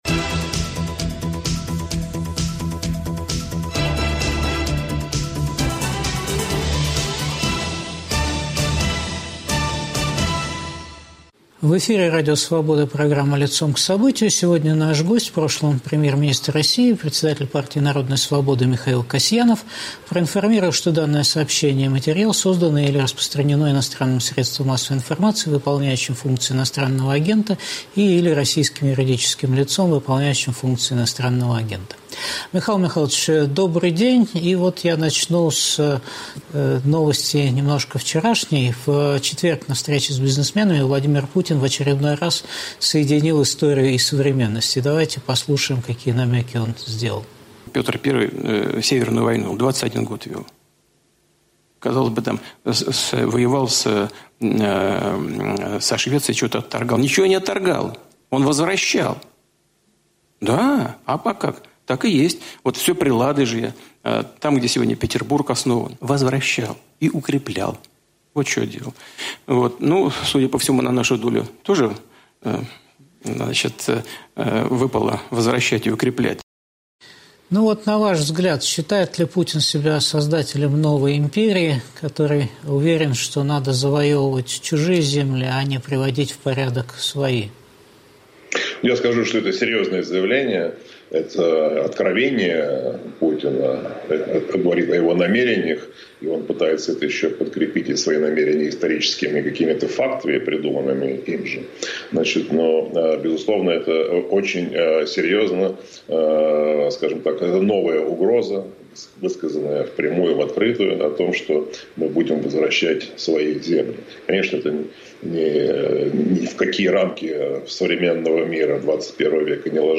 В эфире Михаил Касьянов.